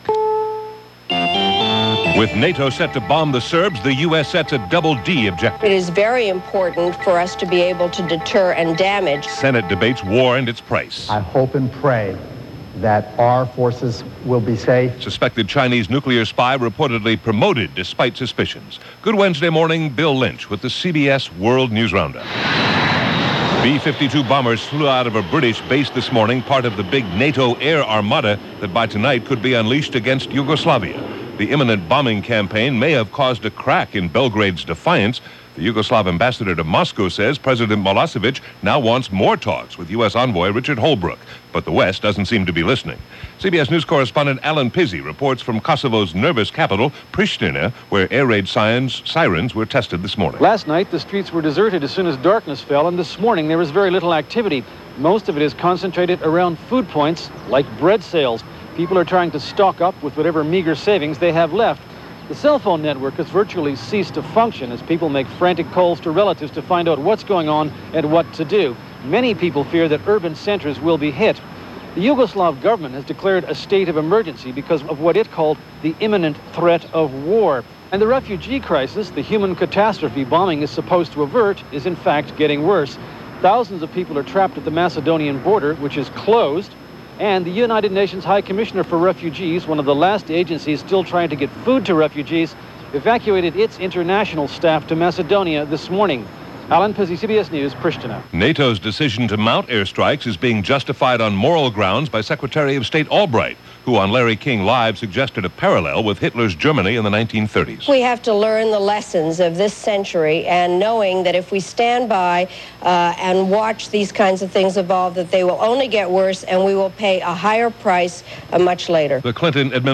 And as reports from Belgrade continued to pour in, that was a small slice of news that happened on this 24th of March 1999, as reported on The CBS World News Roundup.